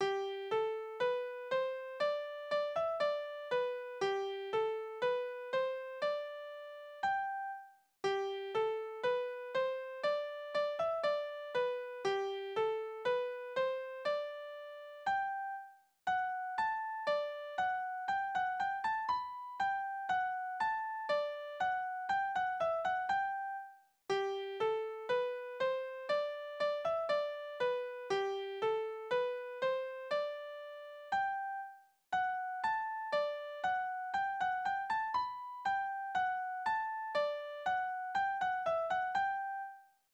« 10895 » As de Jud in't Woaterfehl Tanzverse: Galopp Tonart: G-Dur Taktart: 2/4 Tonumfang: große Dezime Externe Links